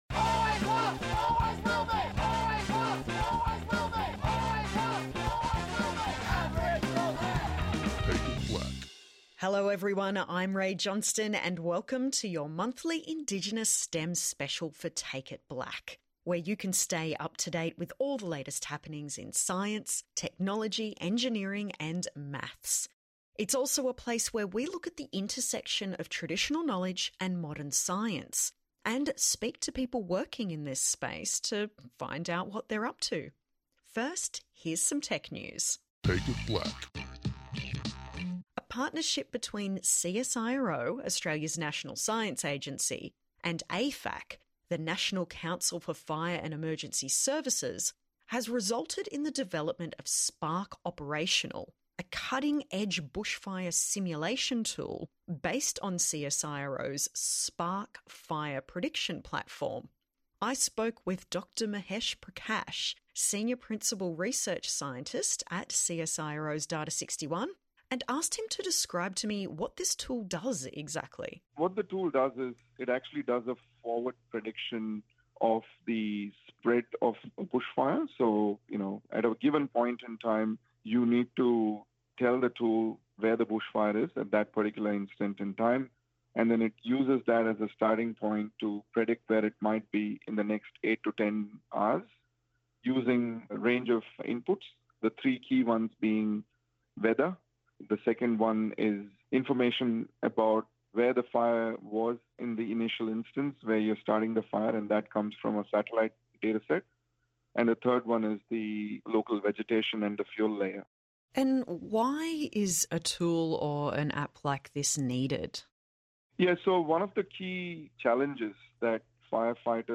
latest STEM news and interviews